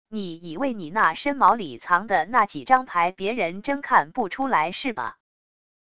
你以为你那身毛里藏的那几张牌别人真看不出来是吧wav音频生成系统WAV Audio Player